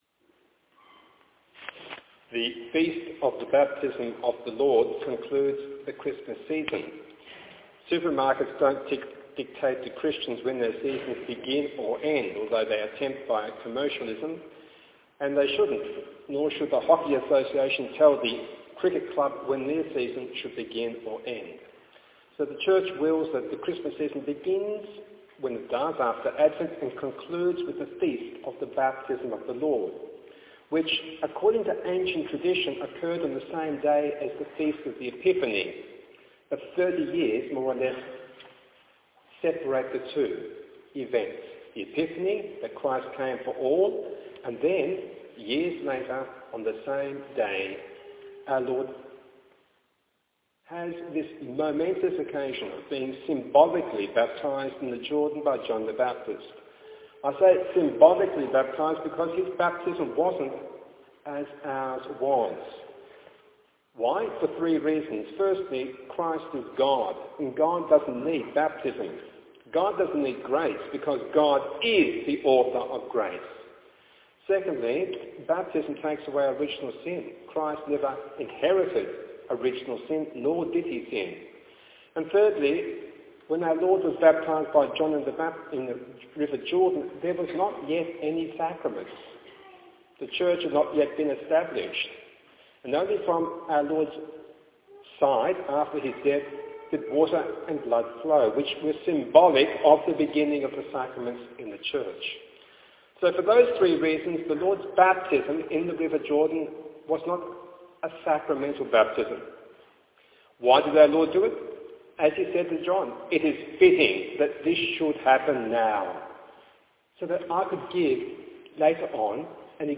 Recorded Homily, Sunday 12th January 2014: